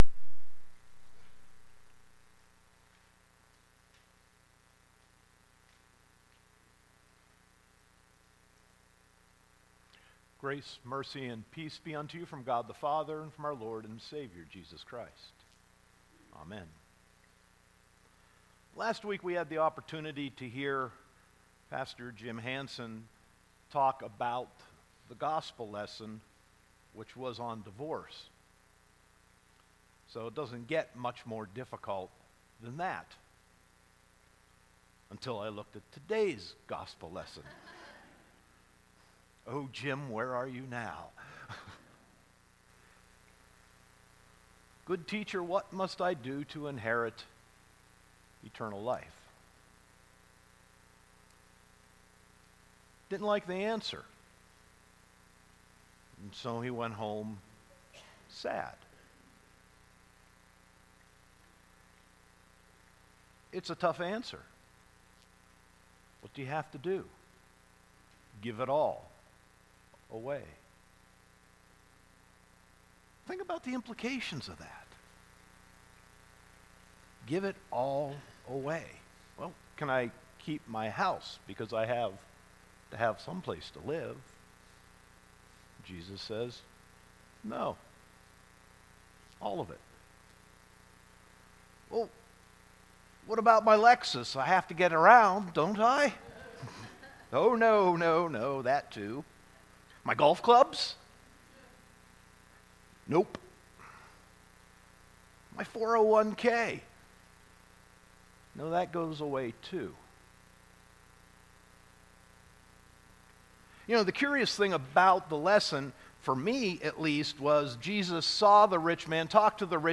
Sermon 10.14.2018